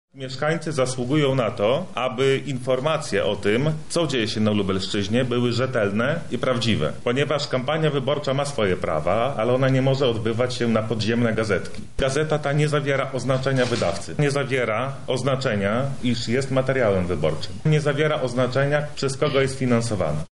Renoma województwa została zszargana – mówi Przemysław Litwiniuk, przewodniczący Sejmiku Województwa Lubelskiego.